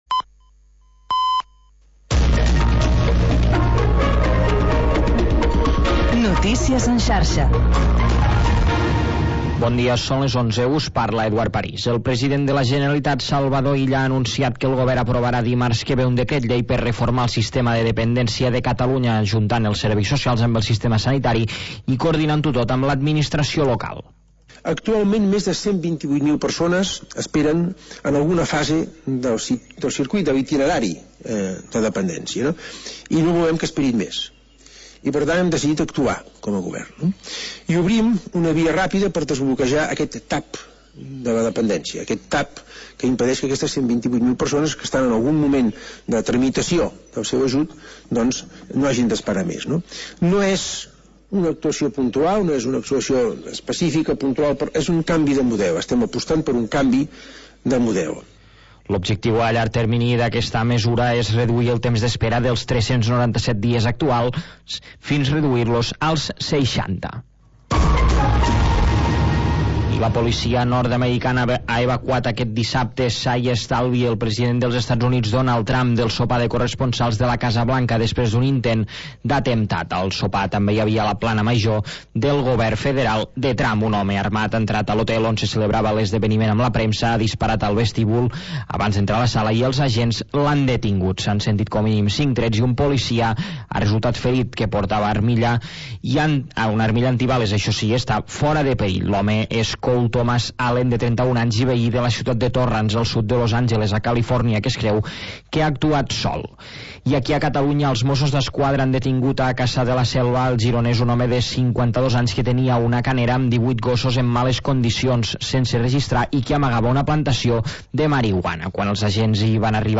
Havanera, cant de taverna i cançó marinera. obrint una finestra al mar per deixar entrar els sons més mariners